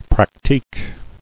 [pra·tique]